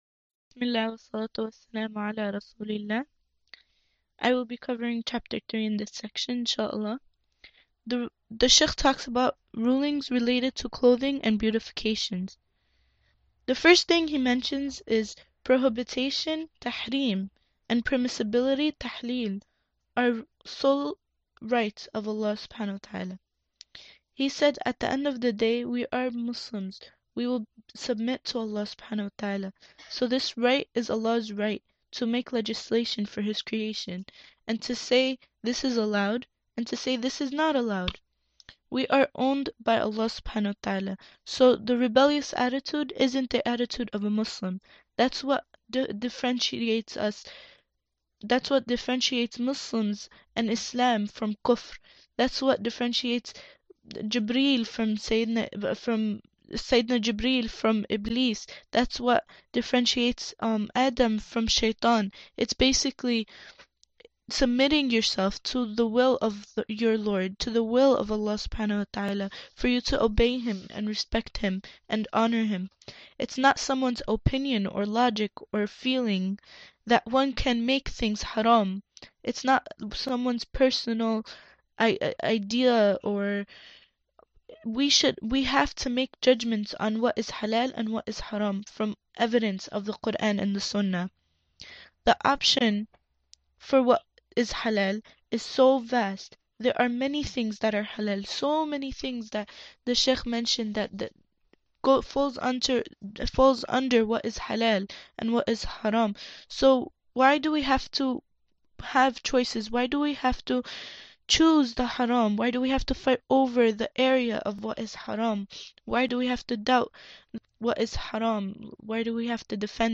Study Session for Chapter 3 of the AlMaghrib Institute seminar, "Complicated", which discussed women's modern Fiqh issues.